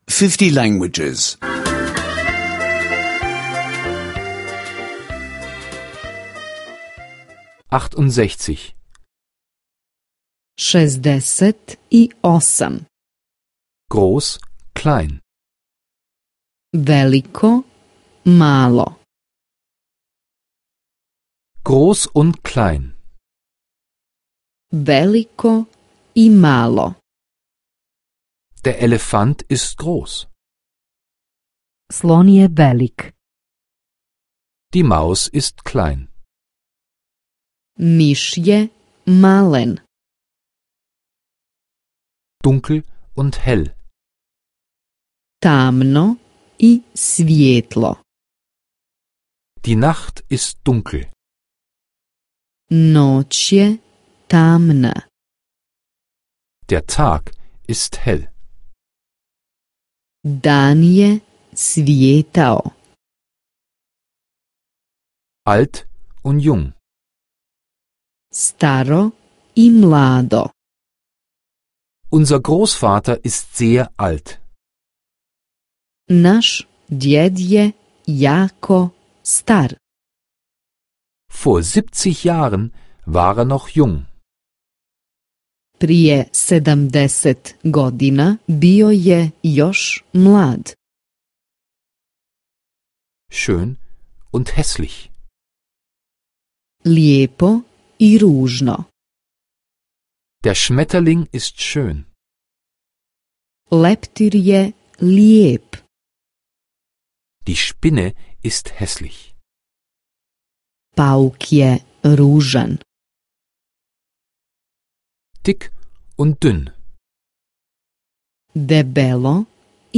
Bosnisch Sprache-Audiokurs (kostenloser Download)